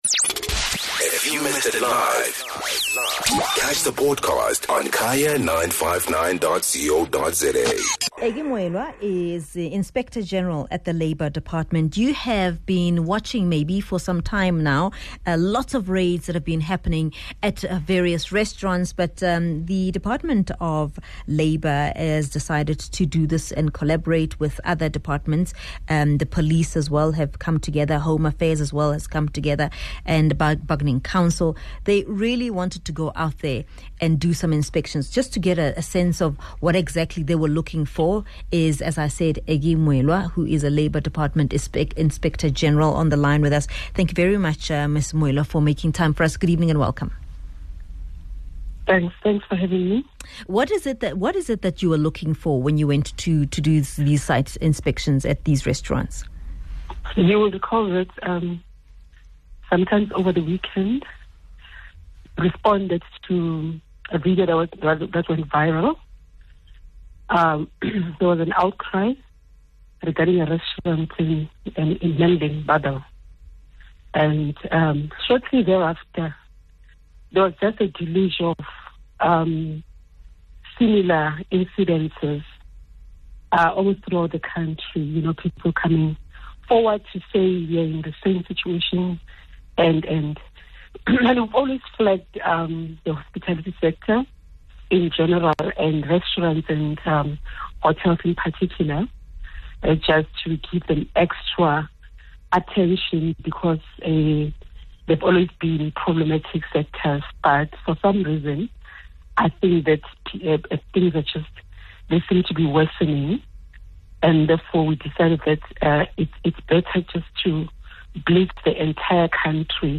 speaks to Inspector General at the Labour Department, Aggy Moiloa.